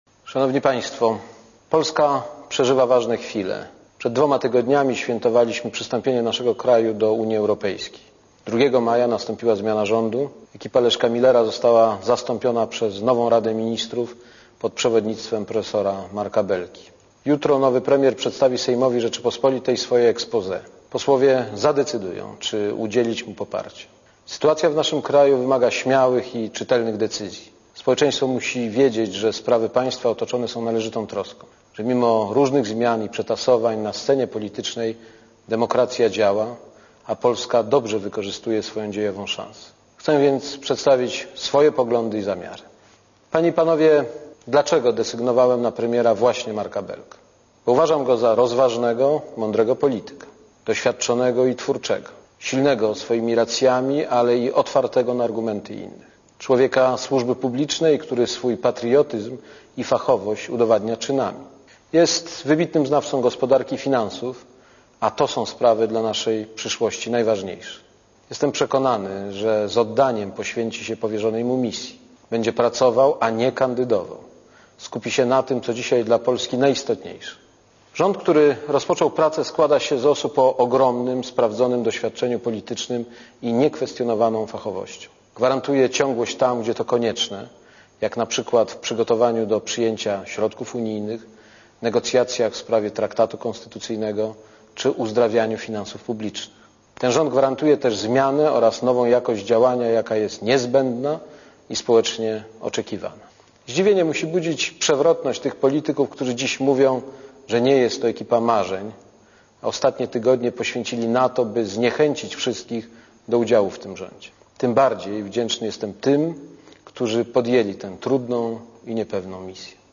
Prezydent Aleksander Kwaśniewski zaapelował do rodaków w wystąpieniu telewizyjnym o "obdarzenie nowego rządu" Marka Belki "kredytem zaufania".
Posłuchaj całego wystąpienia Aleksandra Kwaśniewskiego w TVP (1,48Mb)